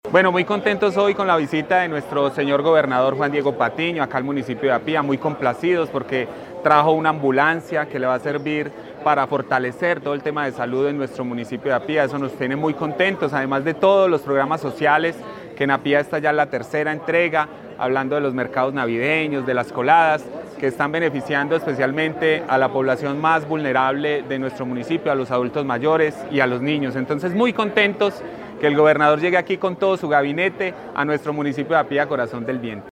La calidez de esta jornada fue respaldada por el alcalde de Apía, Jorge Andrés Hoyos, quien reconoció la importancia de esta iniciativa: